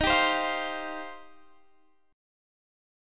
Listen to D#6add9 strummed